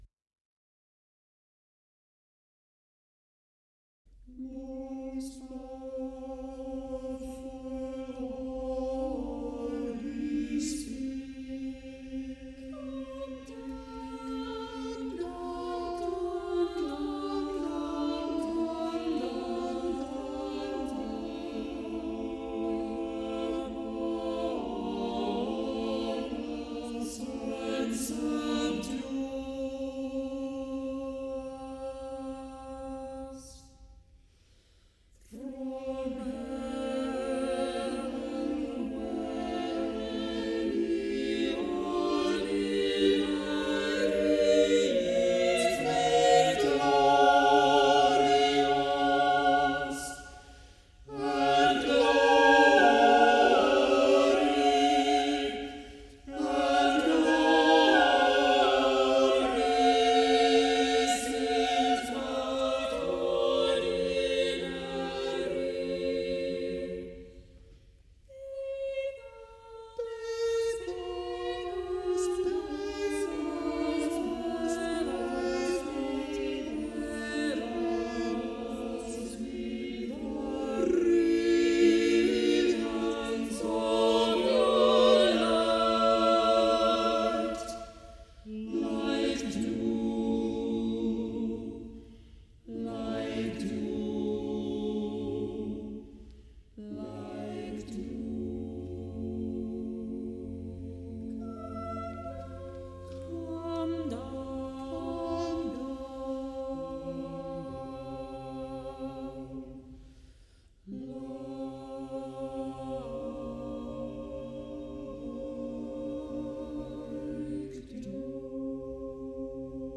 sacred choral compositions and arrangements